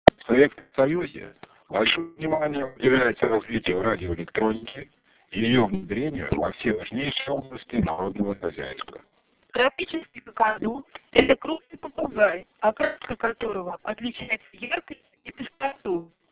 Для сравнения приводятся звуковые файлы, полученные в аналогичных условиях для стандартного вокодера и системы канального кодирования с выходной скоростью цифрового потока 7200 бит/с, используемых в международном стандарте транкинговой связи TETRA.
Вокодер и канальное кодирование системы  TETRA 7200 бит/с.
Канал с группированием ошибок, BER = 7%, Pgb = 3%